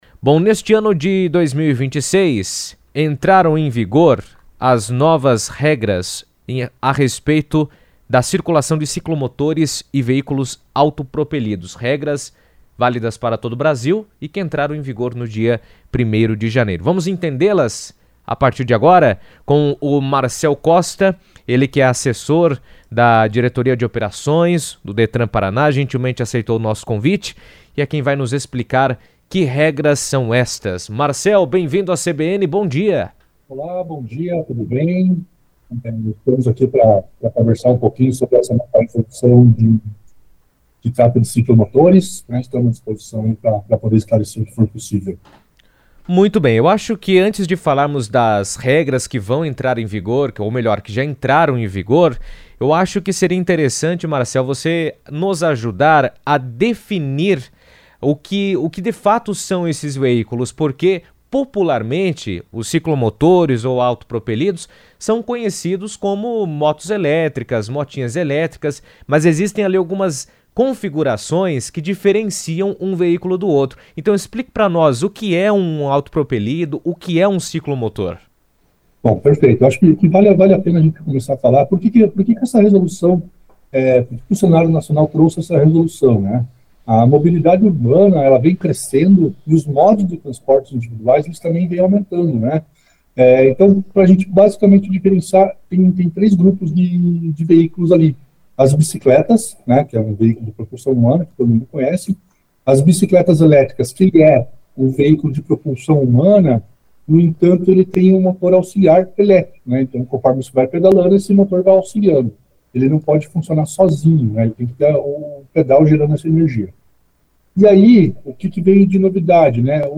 Editoriais
Entrevista